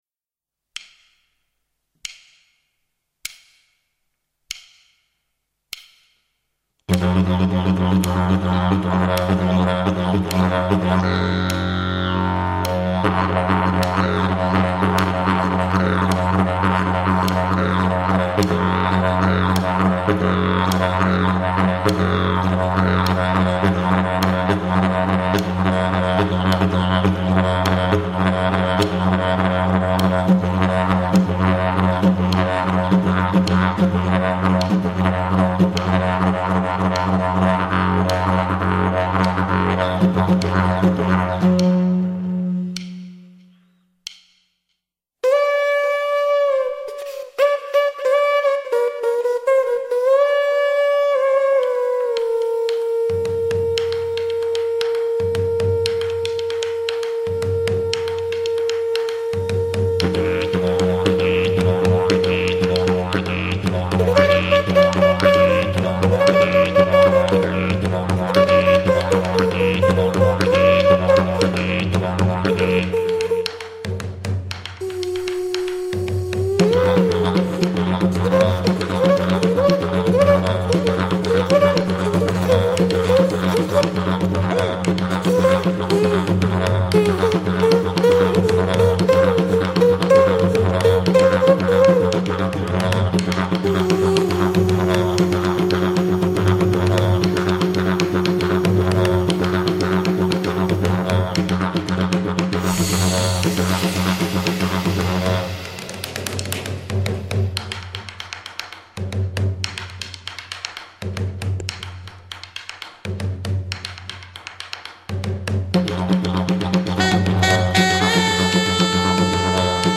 Ce groupe est composé de 3 musiciens :
au chant et à la percu Sénégalaise
trombone